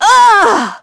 Miruru_L-Vox_Damage_04.wav